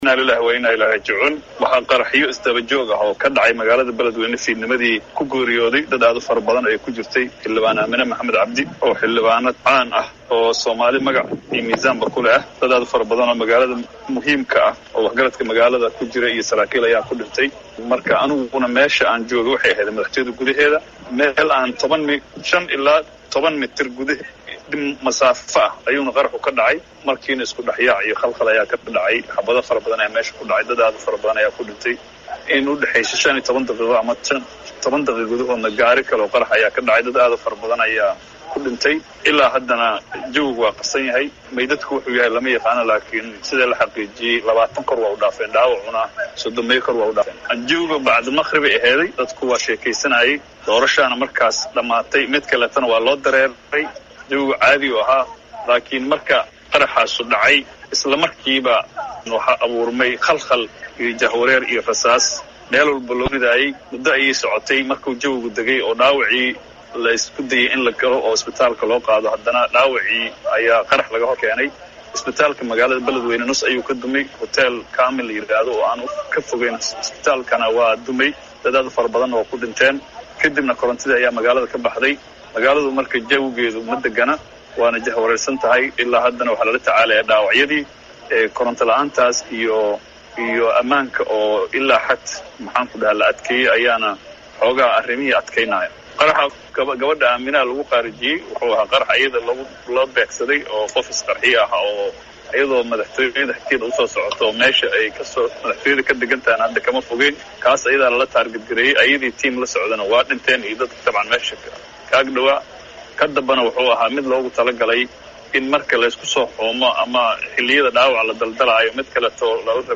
Sanbaloolshe oo la hadlay laanta afka soomaaliga ee VOA-da ayaa sheegay in xilliyada qaraxyadu ay dhacayeen uu ku sugnaa meel waxyar u jirta, islamarkaana dadka ku dhintay ay yihiin, Mas’uuliyiin, Shacab iyo Askar.